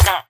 Sound / Minecraft / mob / villager / hit1.ogg
hit1.ogg